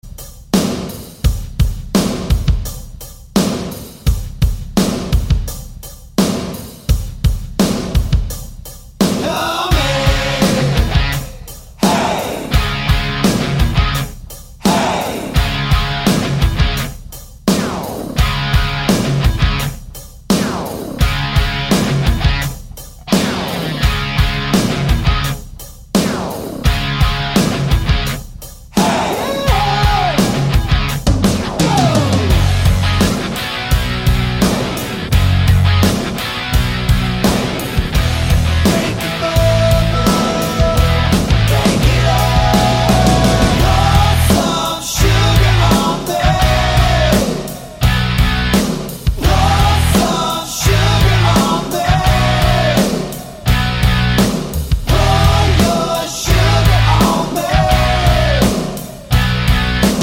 Medley Rock